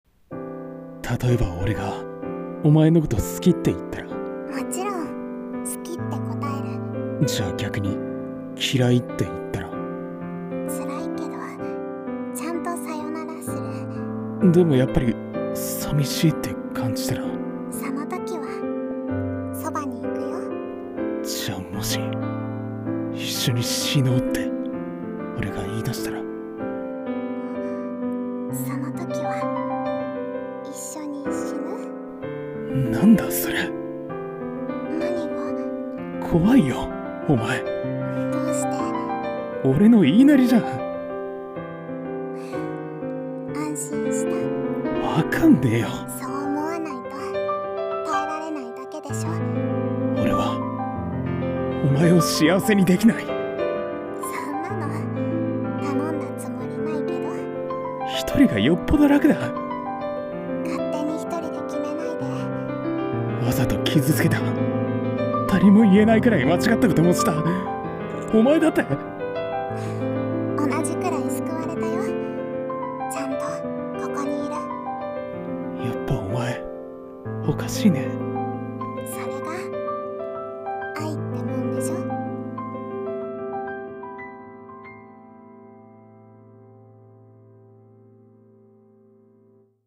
二人声劇【可笑しいね】